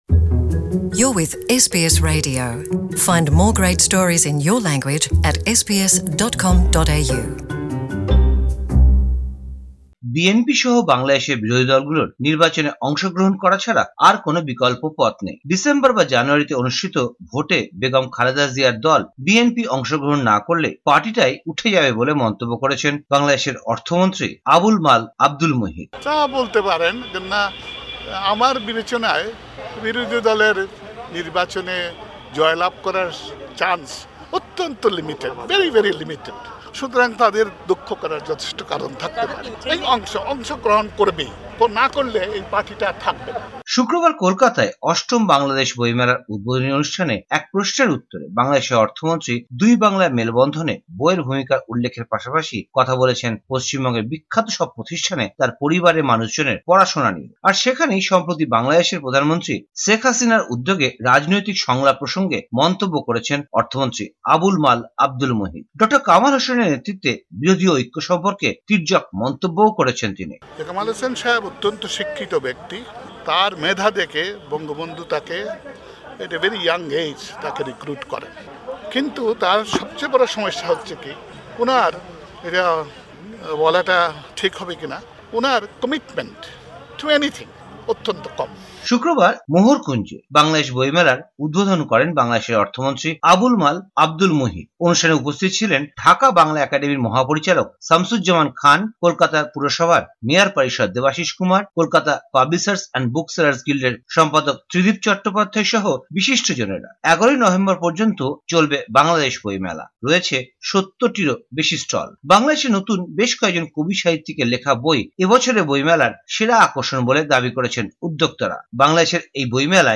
ভারতীয় সংবাদ বিশ্লেষণ: ০৩ নভেম্বর ২০১৮